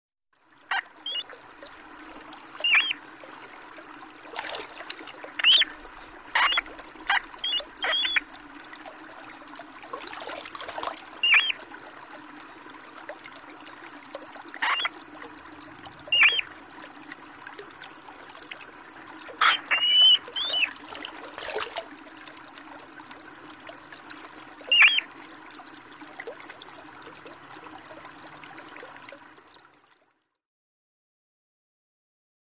На этой странице собраны звуки ондатры (мускусной крысы) — от характерного писка до шумов, которые издают эти животные в природе.
Звук ондатры